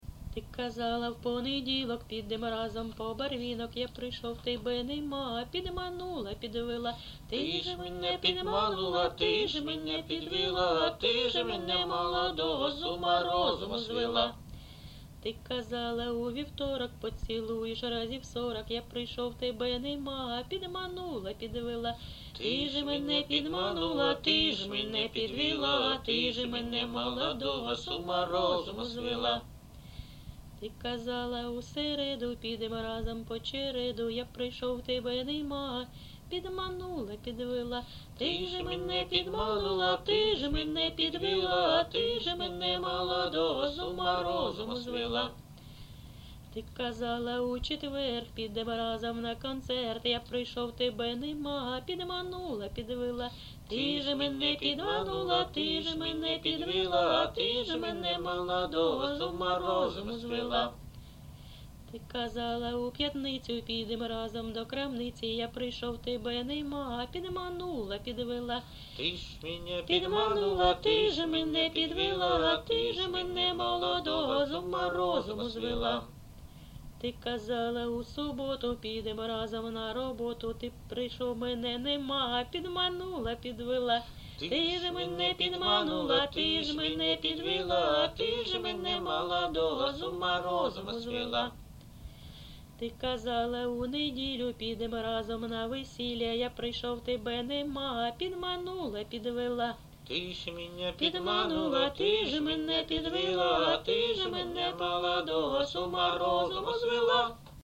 ЖанрЖартівливі
Місце записум. Ровеньки, Ровеньківський район, Луганська обл., Україна, Слобожанщина